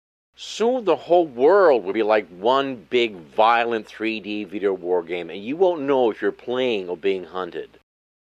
American Accents
Male, 60s
Gen Am